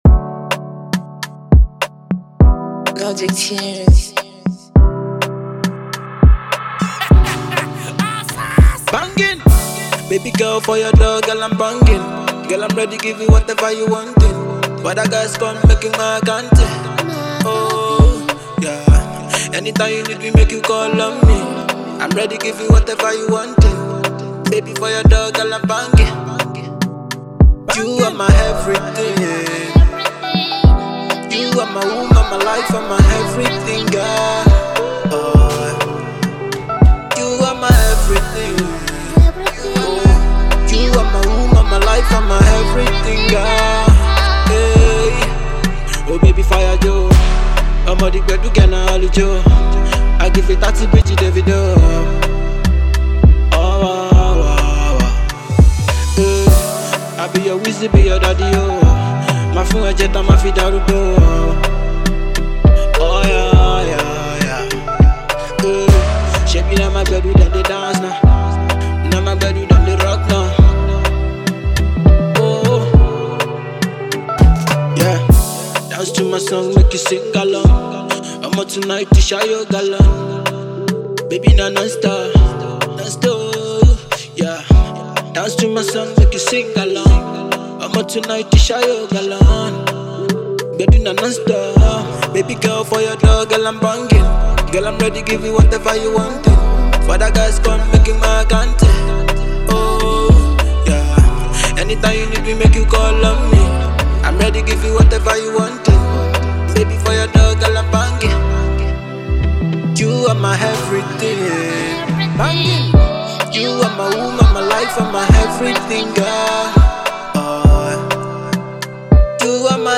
afro hip-hop